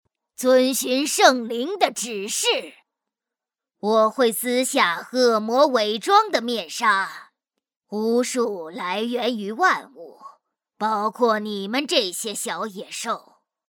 女218-【游戏】中年
女218-温柔甜美 夸张震撼